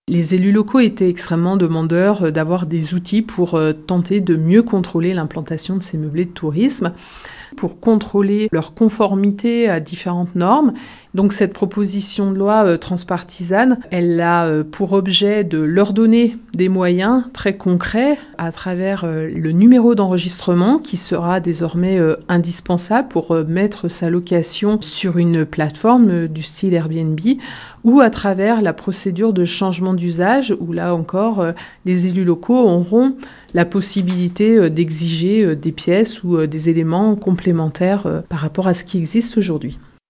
Sylviane Noël est sénatrice de Haute-Savoie, et rapporteure du texte.